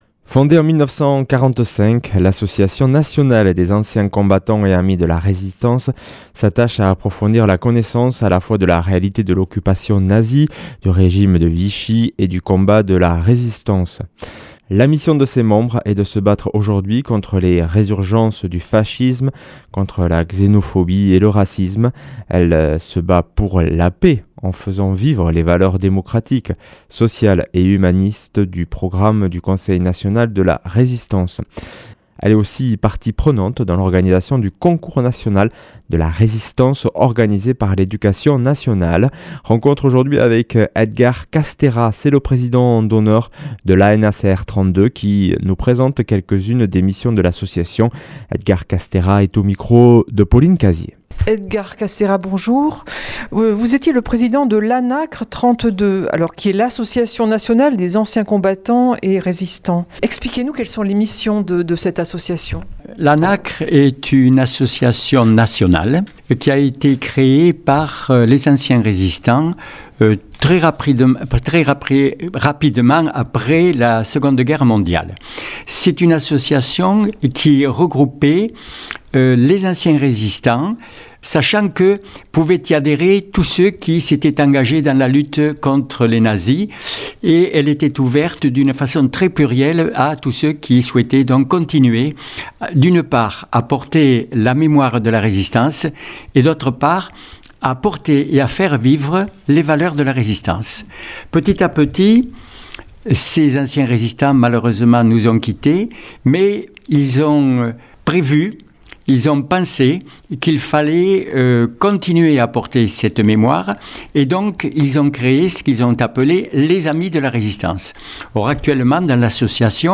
Interview et reportage du 17 avr.